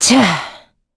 Hilda-Vox_Landing.wav